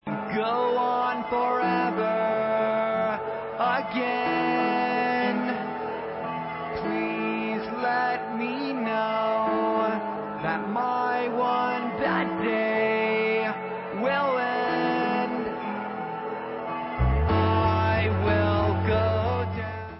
Rock/Punk